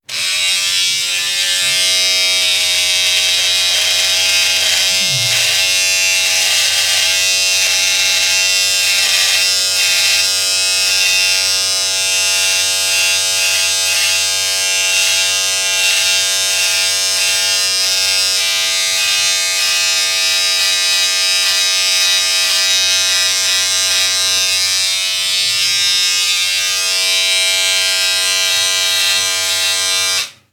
Máquina de afeitar
Sonidos: Hogar